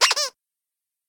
squeak1.ogg